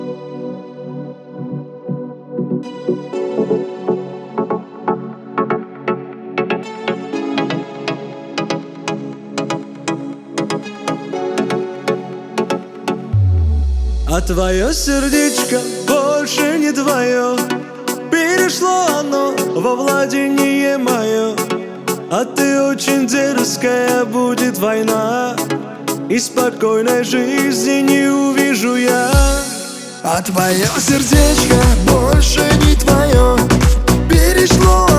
Жанр: Поп / Русские
# Pop